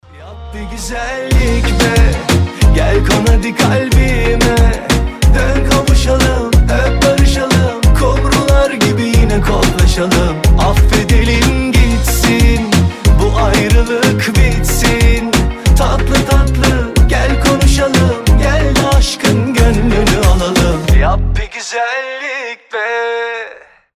• Качество: 320, Stereo
турецкие